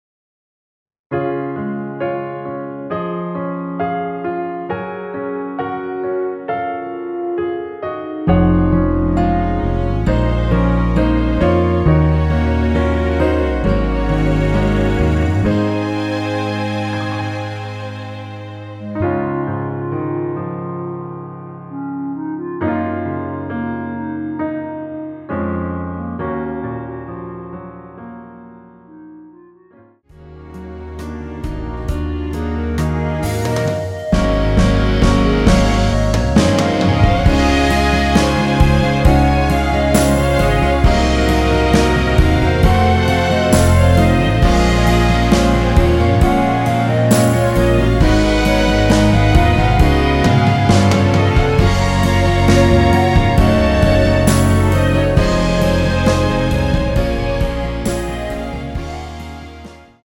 원키 멜로디 포함된 MR입니다.
C#
앞부분30초, 뒷부분30초씩 편집해서 올려 드리고 있습니다.
중간에 음이 끈어지고 다시 나오는 이유는